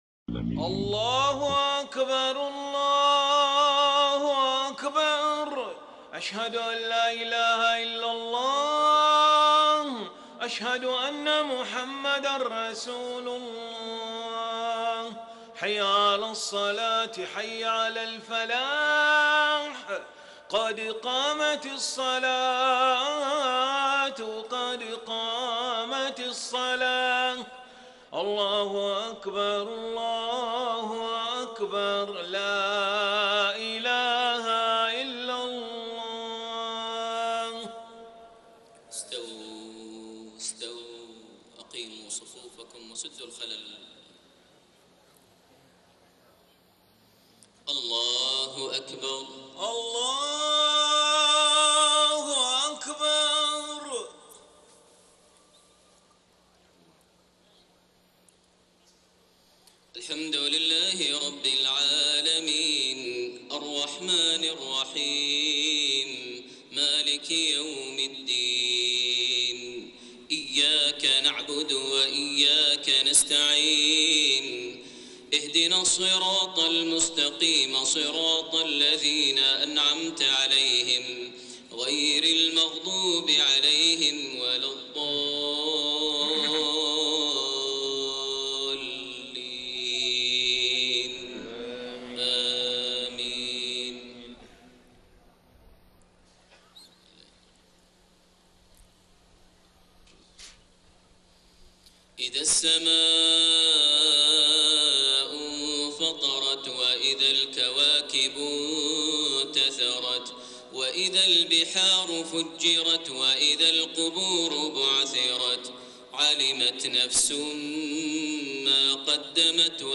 صلاة العشاء 5 شوال 1433هـ سورتي الانفطار و الشمس > 1433 هـ > الفروض - تلاوات ماهر المعيقلي